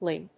音節ling 発音記号・読み方
/líŋ(米国英語)/